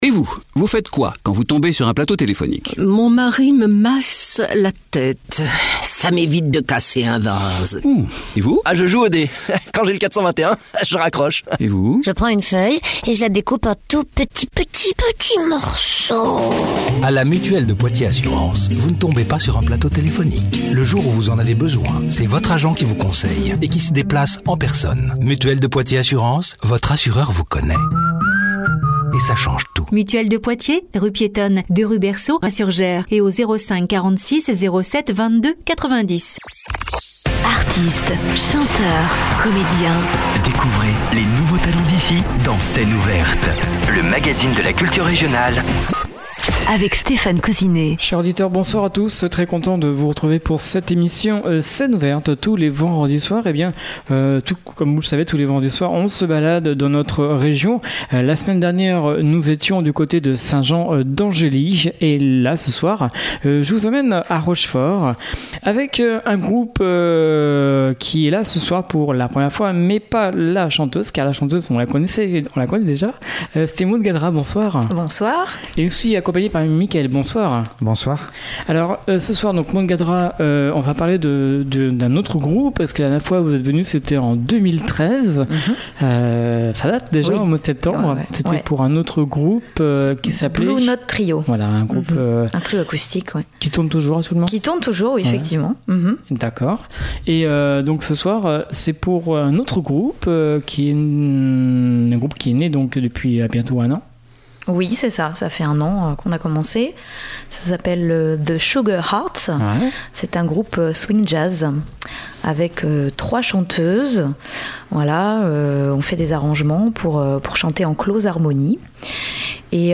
harmonisés à plusieurs voix